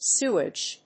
音節sew・age 発音記号・読み方
/súːɪdʒ(米国英語), s(j)úː‐(英国英語)/